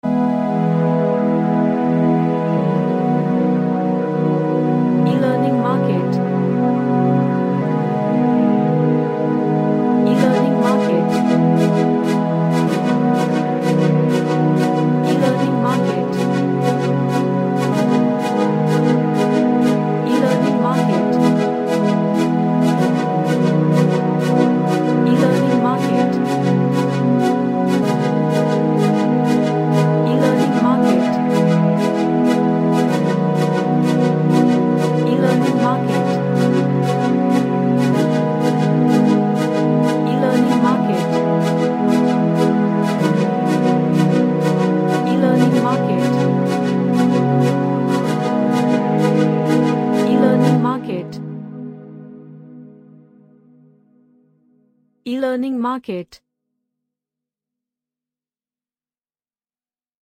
A Relaxing night dream kinda of track.
Relaxation / Meditation